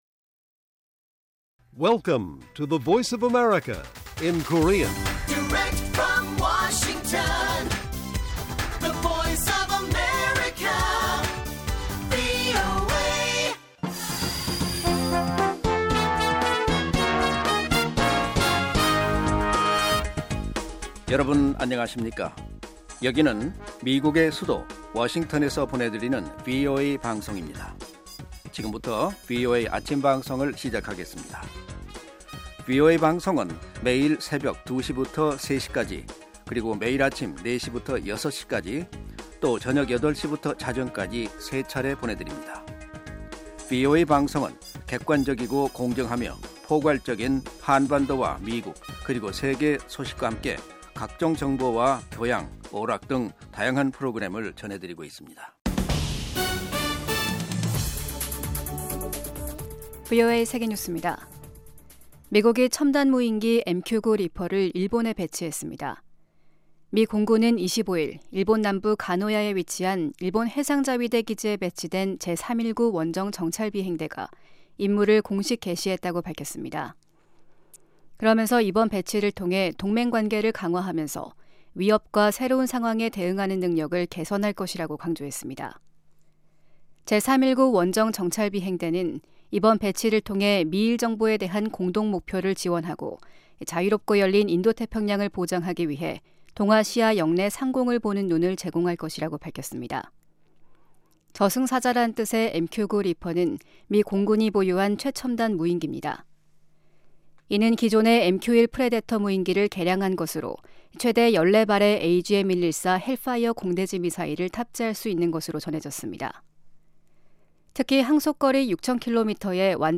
세계 뉴스와 함께 미국의 모든 것을 소개하는 '생방송 여기는 워싱턴입니다', 2022년 10월 28일 아침 방송입니다. '지구촌 오늘'에서는 조 바이든 미국 대통령과 아이작 헤르조그 이스라엘 대통령이 백악관 회담을 통해 이란 핵개발 문제를 논의한 소식 전해드리고, '아메리카 나우'에서는 마크 메도스 전 백악관 비서실장이 '조지아주 선거 외압'에 관해 대배심에서 증언하게 된 이야기 살펴보겠습니다.